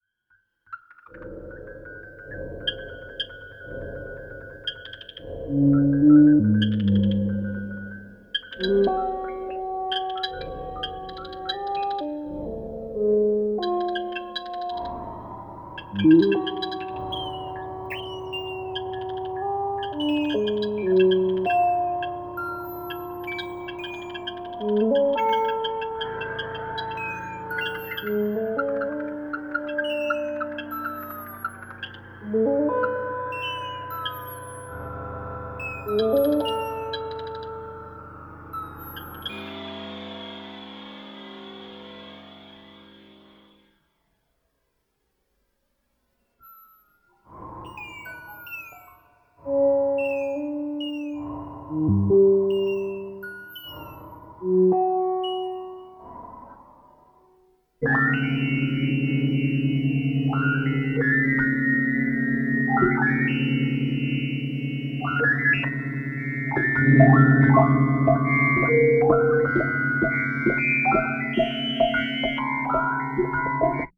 The atonality of the score is profound